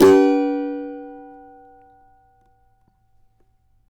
CAVA G#MJ  D.wav